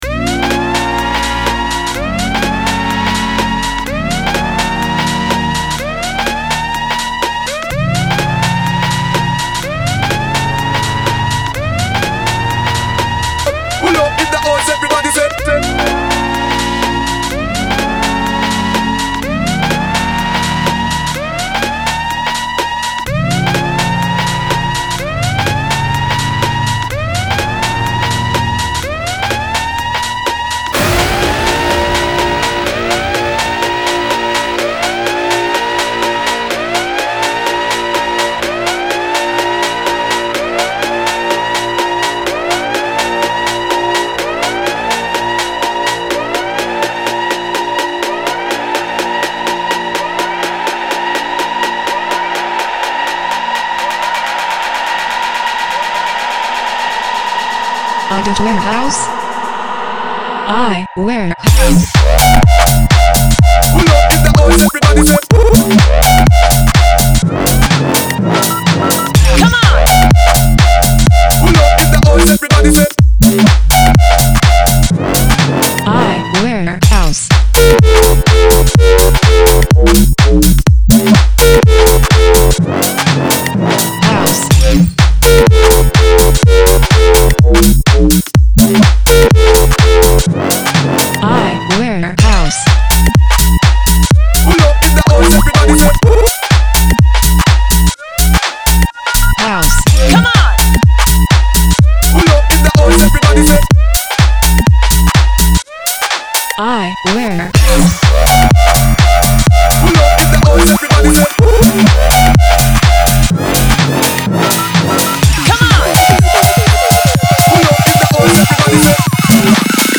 چند آهنگ بیس دار مخصوص ماشین برای شما